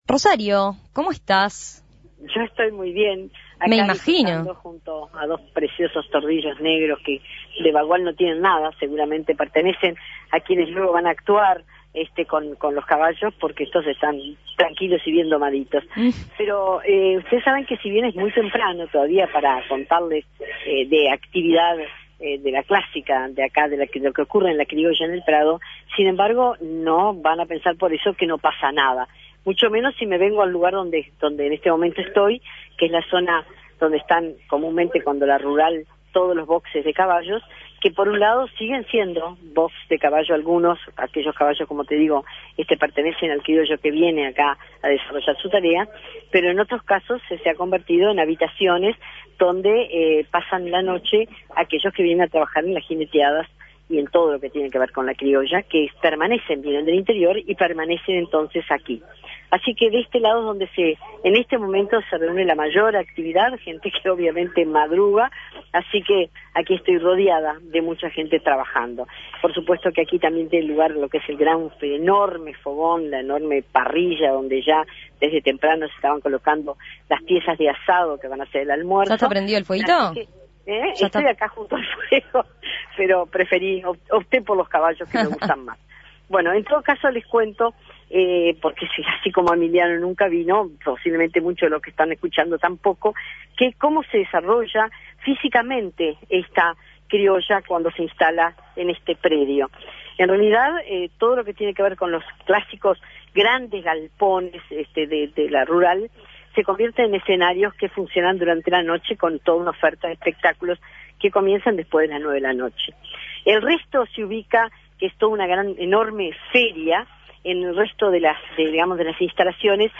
Recorrido por la Criolla del Prado / Cómo empiezan el día los participantes de las jineteadas